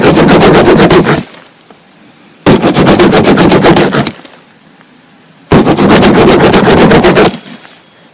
在纸板箱上使用披萨刀
描述：旨在复制某人切好'za'的美味声音。